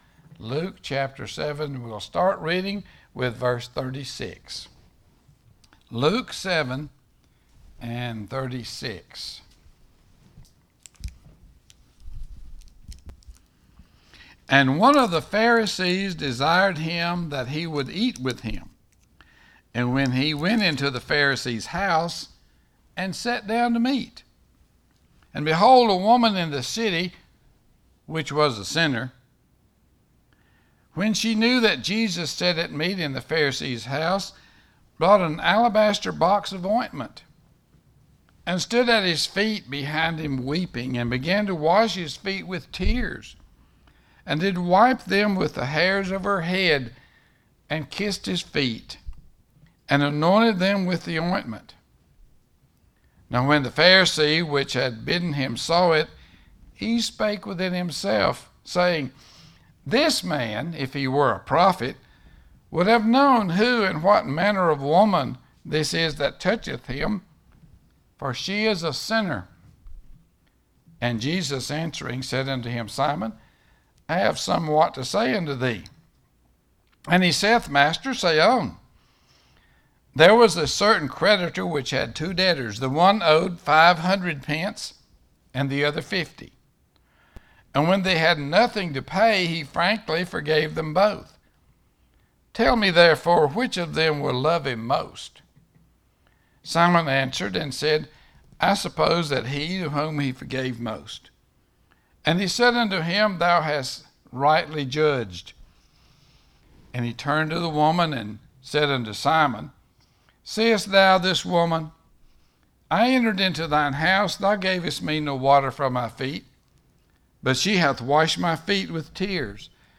SERMONS - Broadway Baptist Church